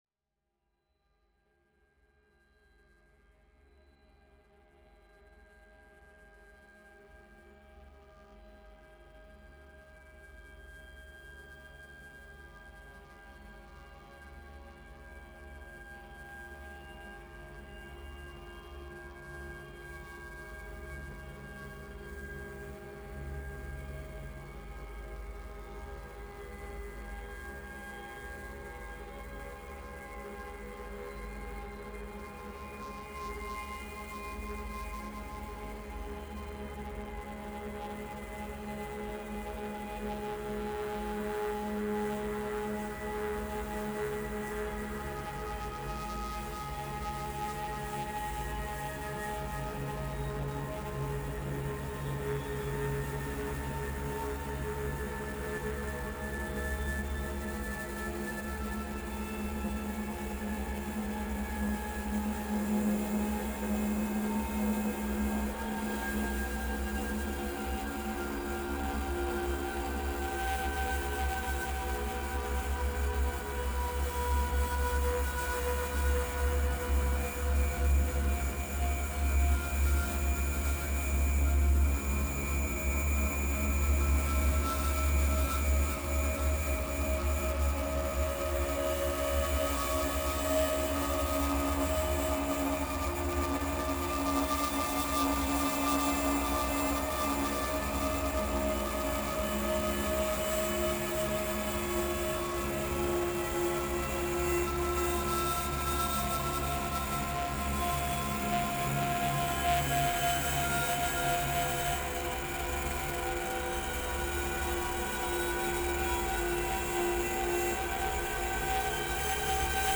透明感溢れる艶やかな音響菌糸が縺れ合う。
魂を静かに浸食するデス・アンビエント。